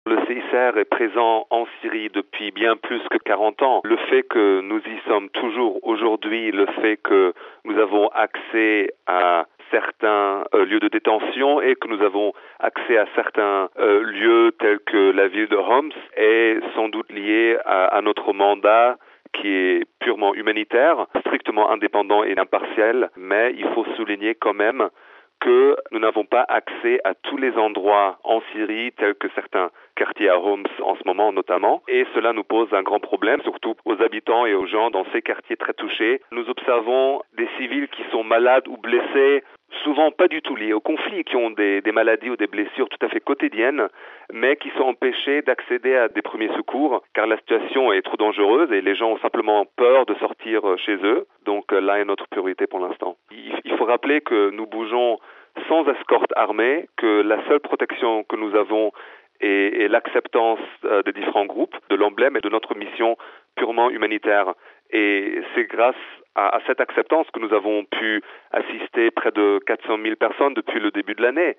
raconte les difficultés d’accéder à certains quartiers.